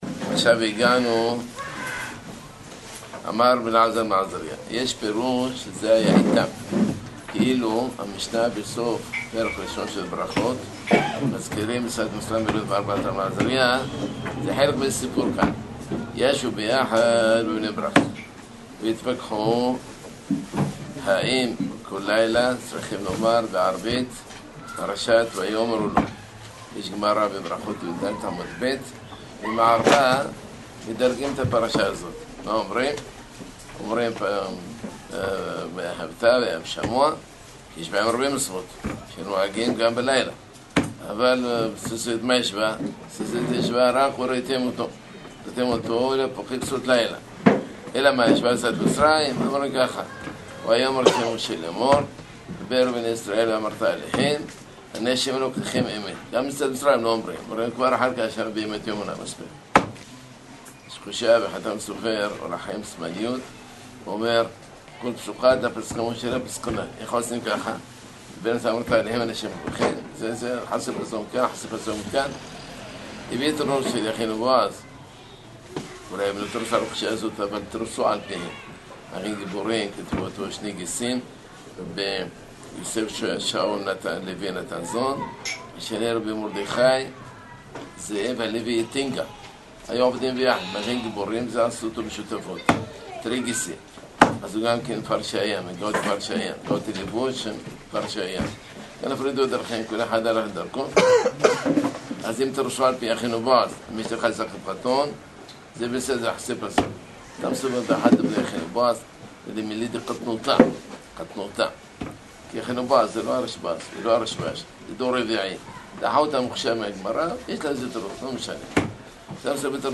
שיעור קצר שנמסר בין מנחה לערבית, בענייני – ההגדה של פסח חלק ג – תש''ע